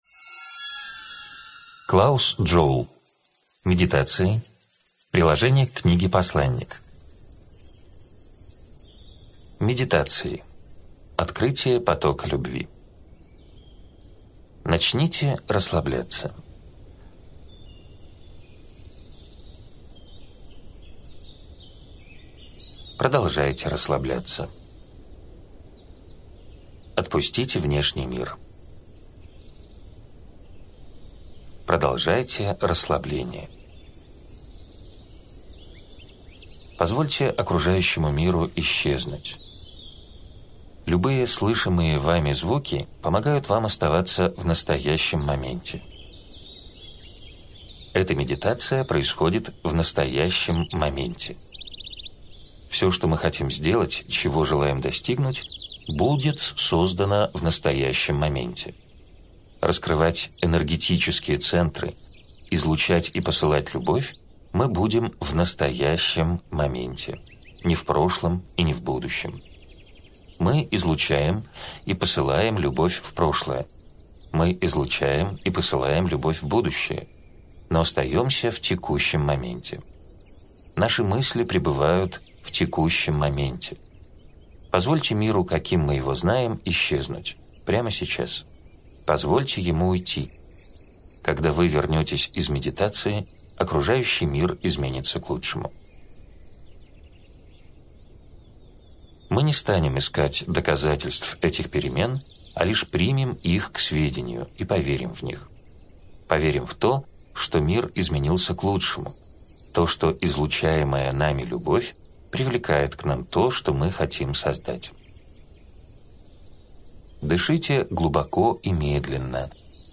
Аудиокнига Медитации. О любви | Библиотека аудиокниг